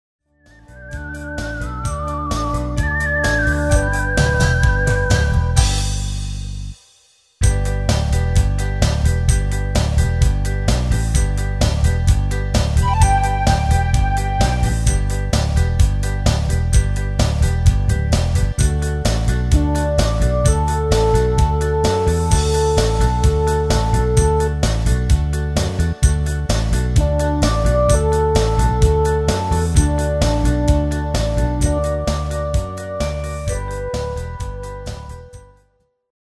Demo/Koop midifile
Genre: Duitse Schlager
- Géén vocal harmony tracks